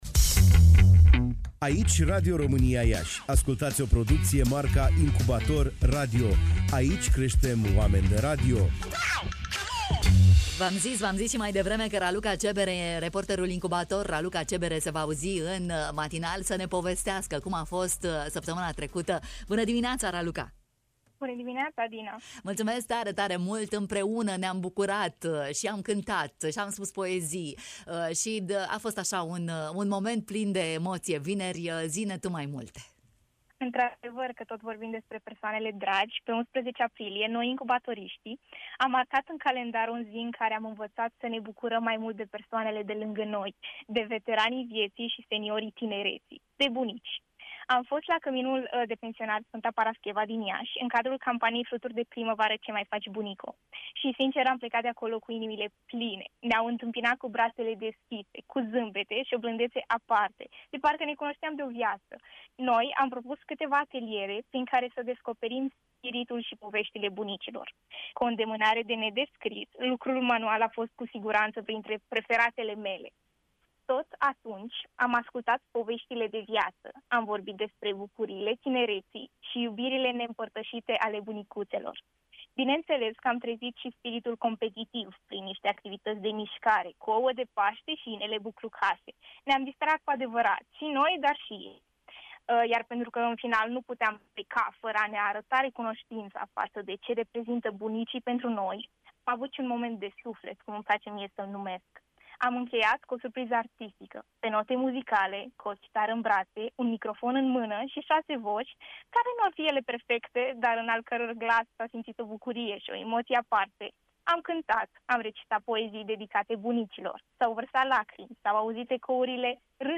Evenimentul s-a desfășurat la Căminul de Pensionari „Sf. Parascheva” Iași.